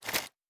goodcircle/IdleRPG2024 - Assets/_8Sound/Grenade Sound FX/Different sounds/Throw6.wav at c111c38667e5bd2f6848d1ef4c15c01eaa5fd40c - IdleRPG2024 - GoodCircle
Throw6.wav